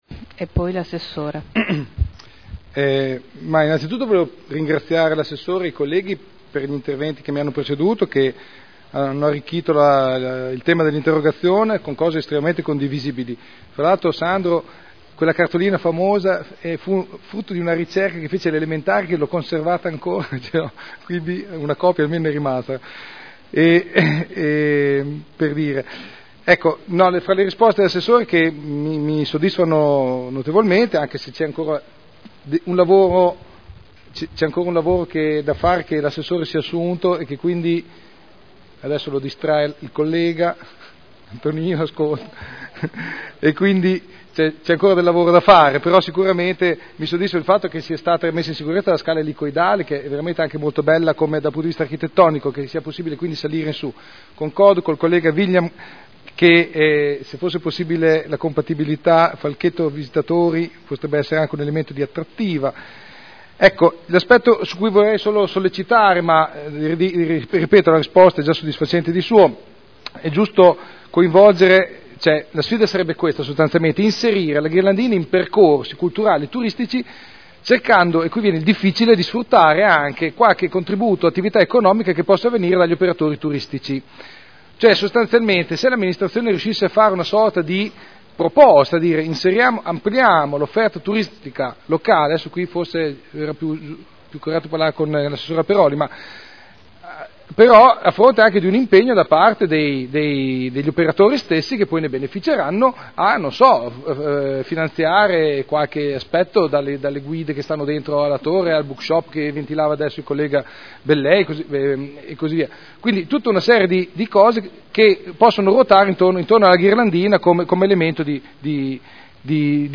Seduta del 13 febbraio Interrogazione presentata dal consigliere Artioli (P.D.) avente per oggetto: “Valorizzazione ed “utilizzazione” della torre civica Ghirlandina” Replica